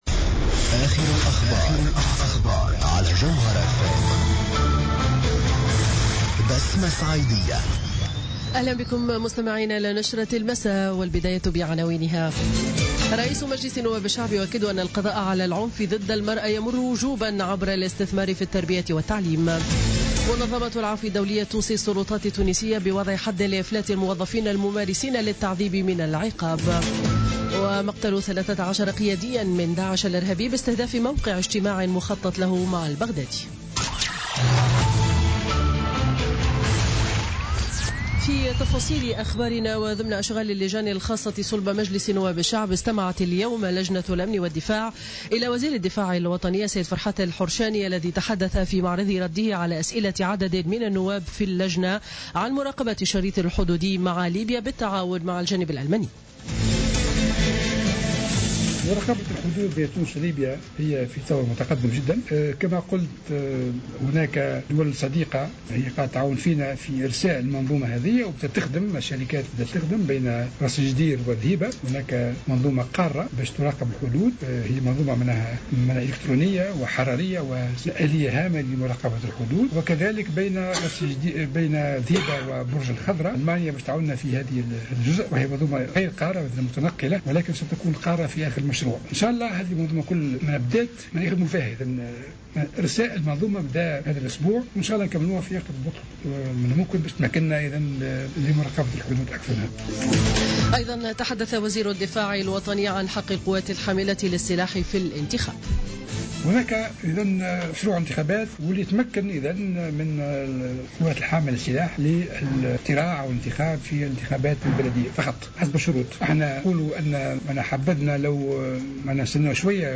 نشرة أخبار السابعة مساء ليوم الاثنين 13 فيفري 2017